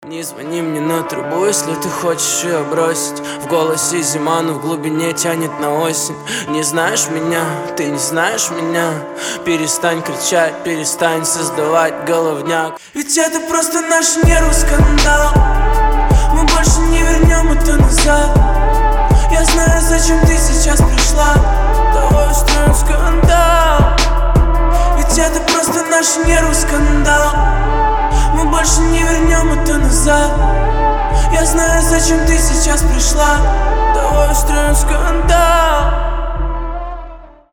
• Качество: 320, Stereo
лирика
Хип-хоп
грустные